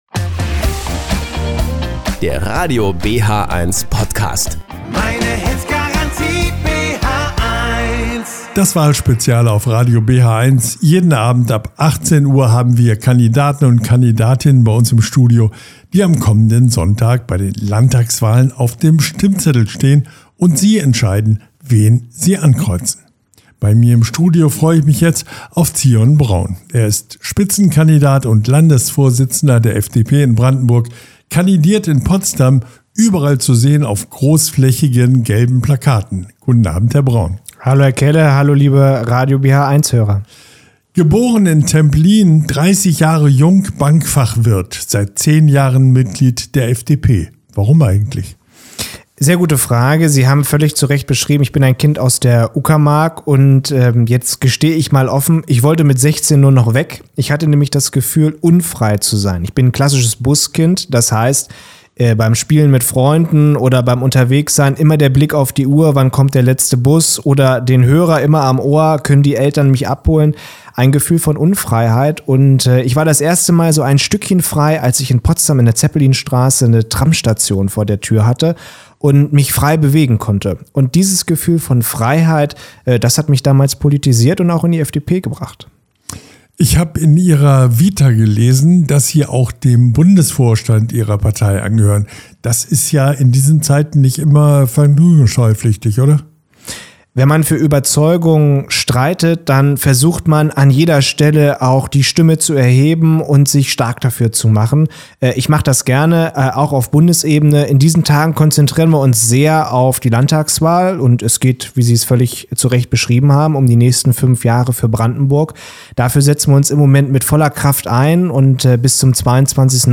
im Gespräch